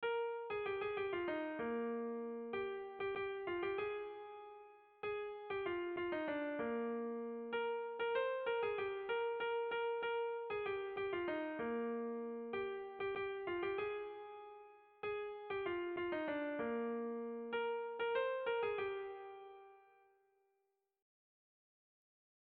Irrizkoa
Zortziko txikia (hg) / Lau puntuko txikia (ip)
ABAB